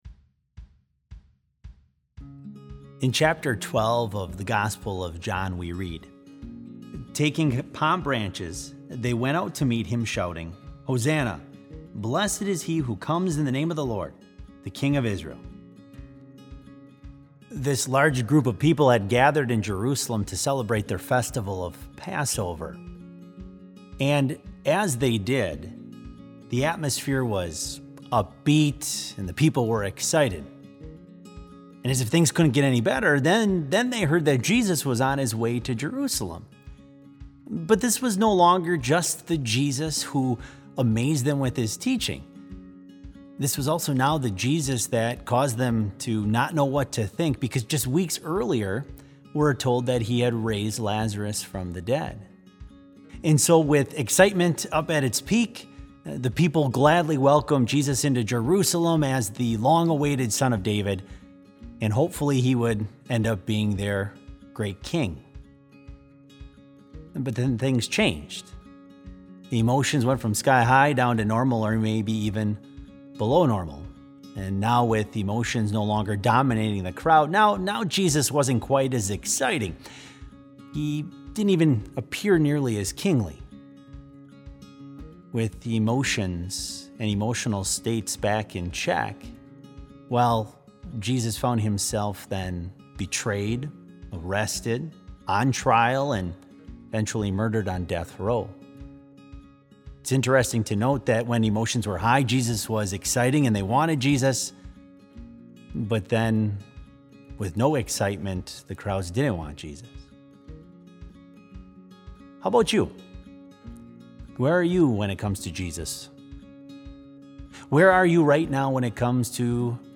Special in Trinity Chapel, Bethany Lutheran College
Complete service audio for BLC Devotion - April 3, 2020